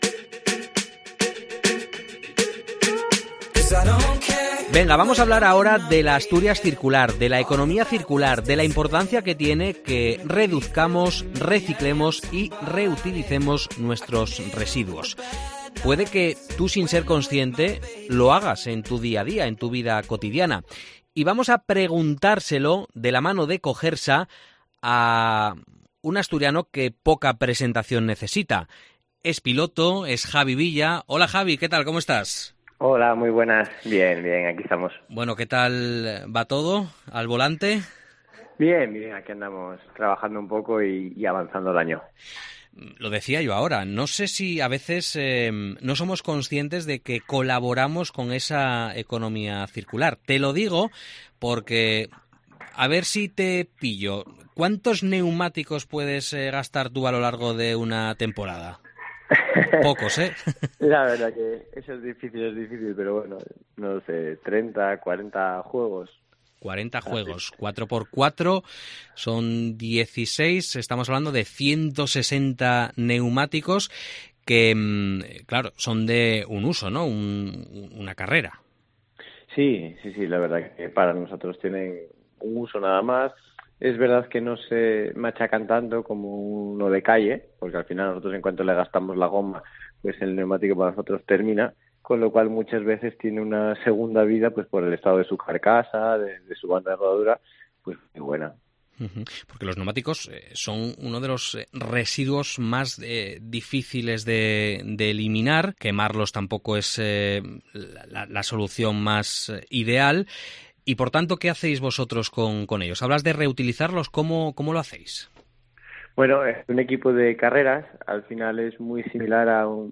Lo hacemos a través de la conversación con asturianos y asturianas que son líderes en sus sectores profesionales (hostelería, deporte, moda, arte, cultura, ciencia…) y que además, enarbolan un compromiso claro con el medio ambiente y la sostenibilidad.